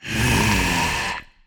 burer_aggressive_0.ogg